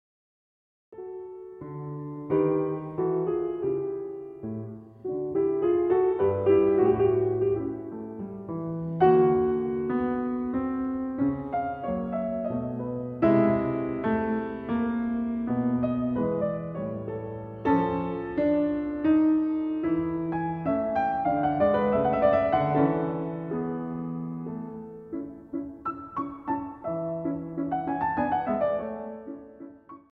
short pieces for the piano